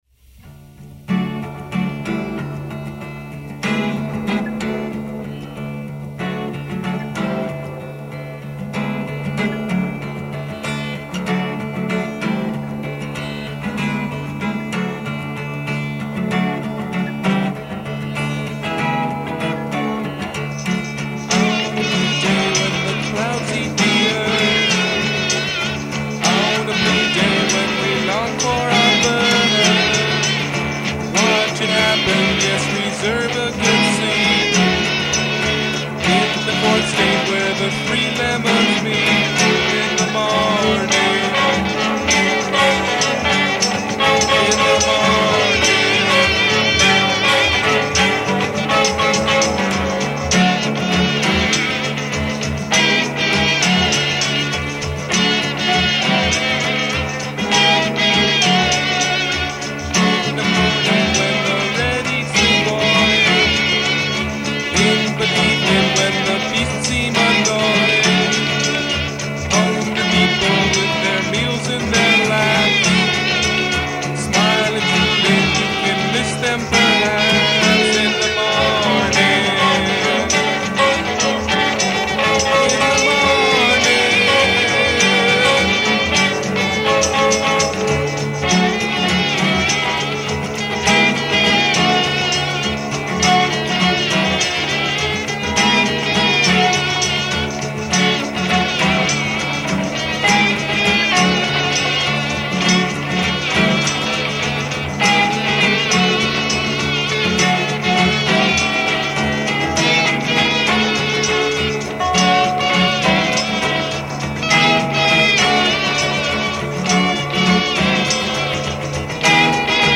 guitars, vocal
kazoo, tambourine, maracas